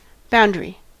Ääntäminen
IPA : /ˈbaʊndɹi/